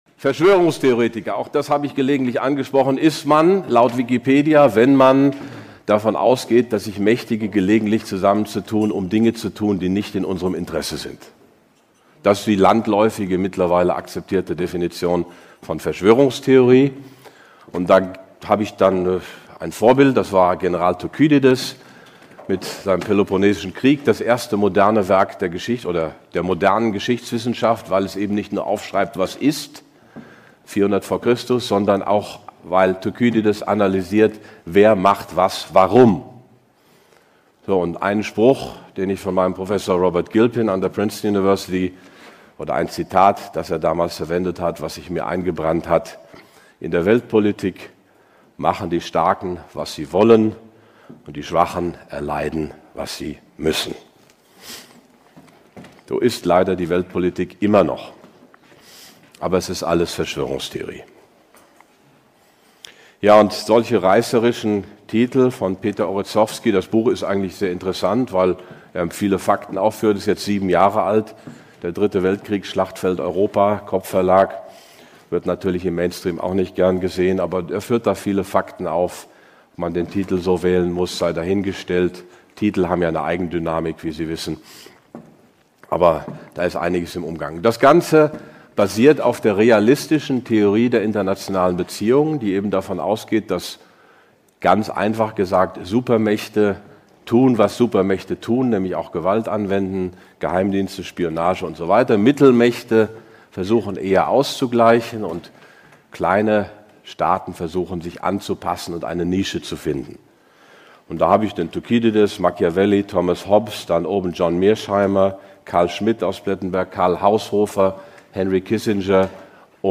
Präsentation führt er die wichtigsten Erkenntnisse dazu aus.